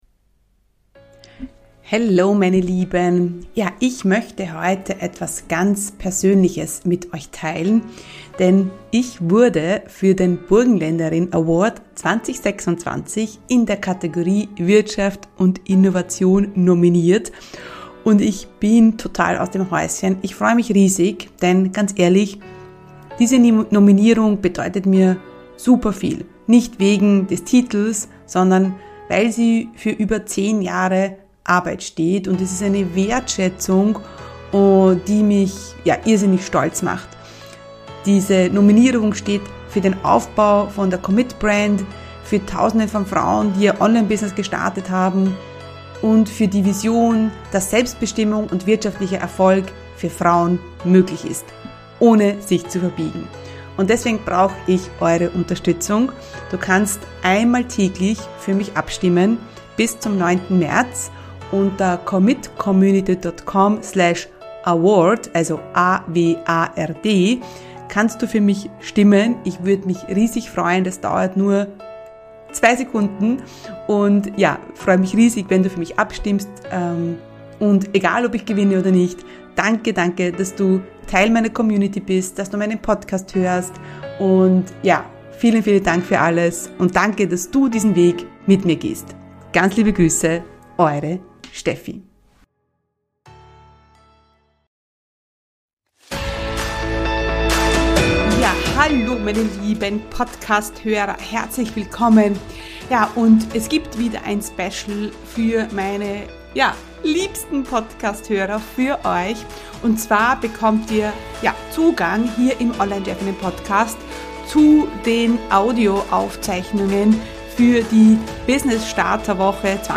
345 Online Business Workshop Woche: Dein Online Business das läuft (Aufzeichnung Tag 4) ~ Online Chefinnen Podcast